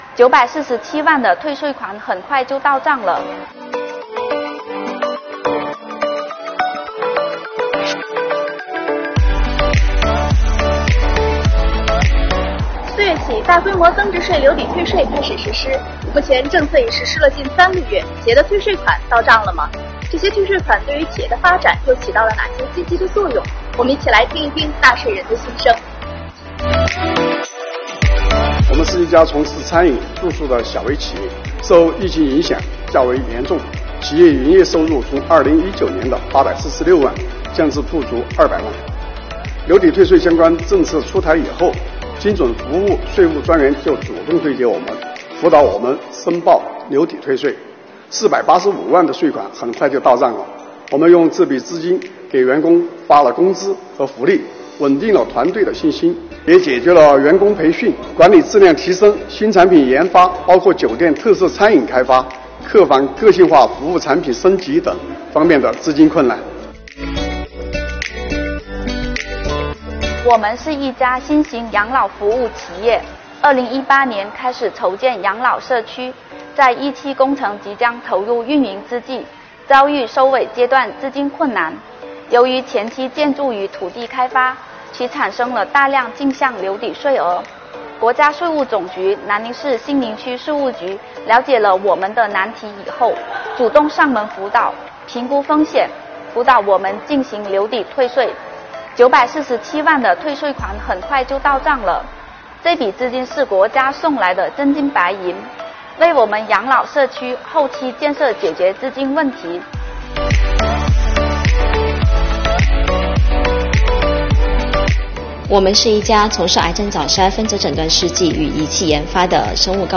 为此，中国税务报记者采访了成都、南宁、深圳、厦门的几家企业。员工培训、提前开业、增加研发投入、购买设备材料……企业准备把钱花到这些地方↓↓↓